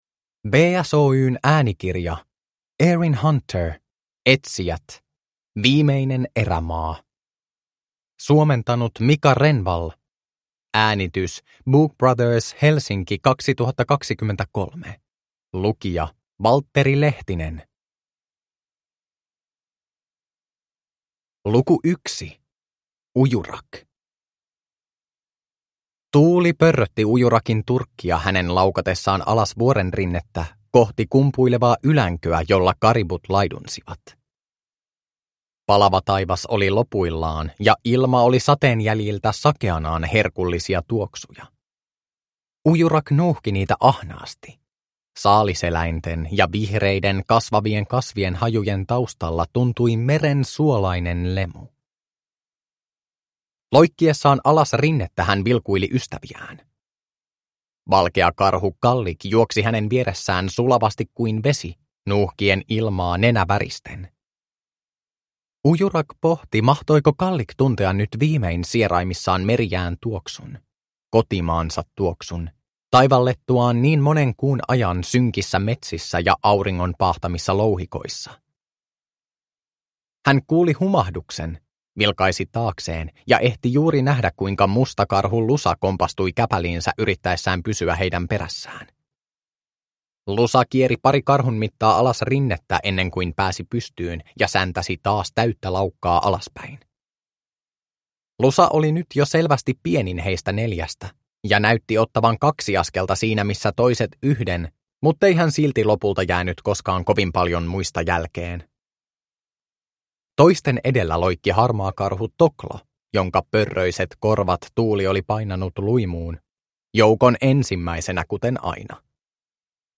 Etsijät: Viimeinen erämaa – Ljudbok – Laddas ner
Uppläsare